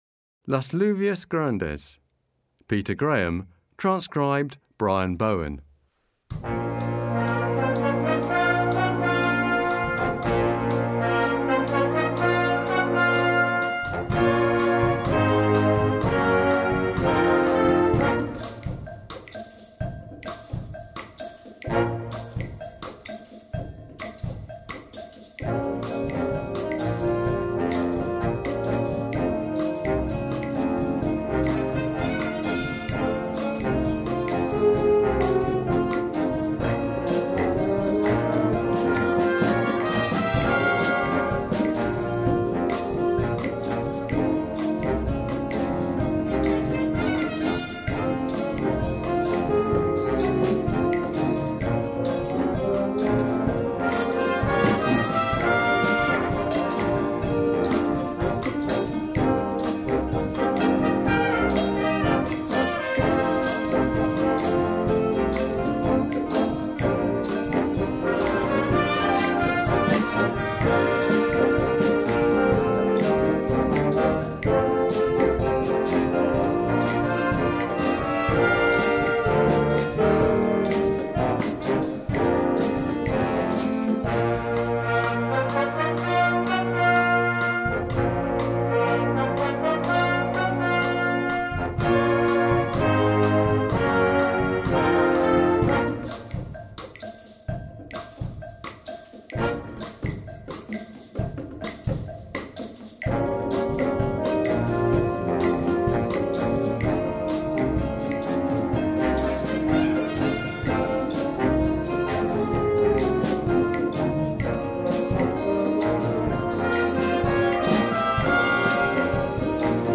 Genre: Band
Percussion 1 (Maracas, Claves)
Percussion 2 (Shaker, Cowbell)
Percussion 3 (Bongos)
Percussion 4 (Drum Set)